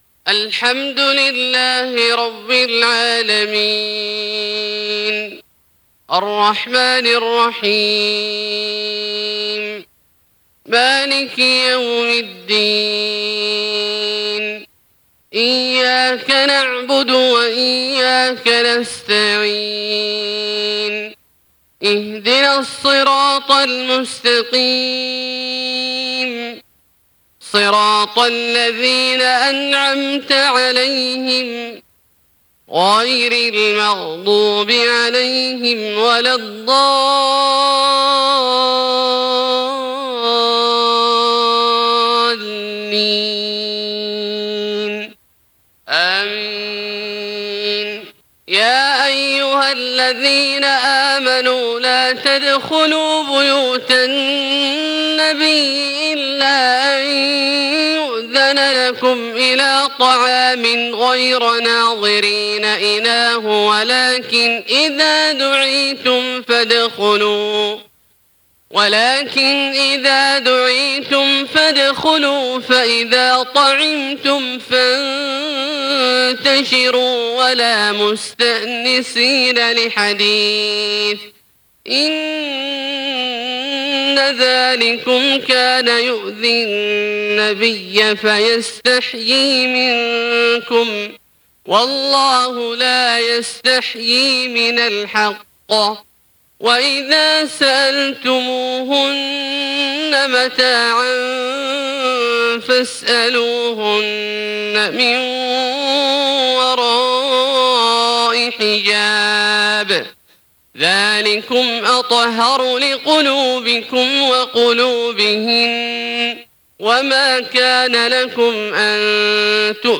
صلاة الفجر 1 - 3 - 1436 تلاوة من سورة الأحزاب .